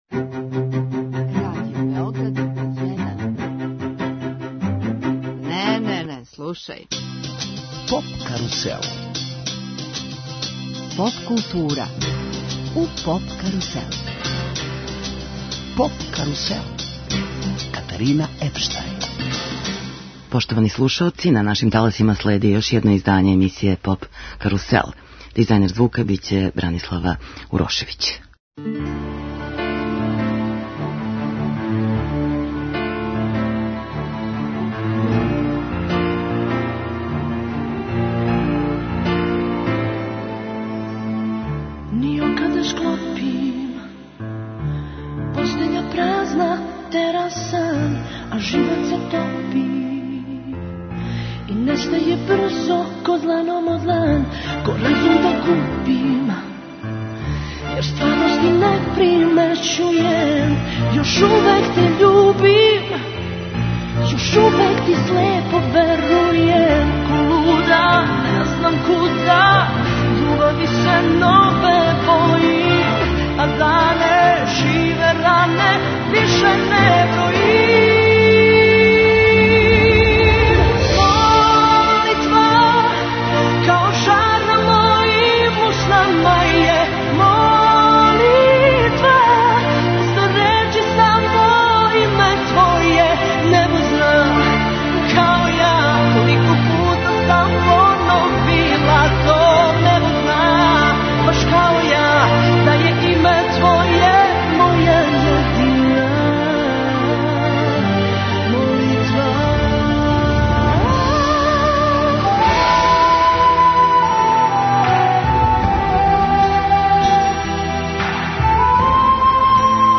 Емисија 'Поп карусел' је посвећена Евросонгу а музиком подсећамо на победнике, највеће хитове и домаће представнике, највећег европског музичког такмичења.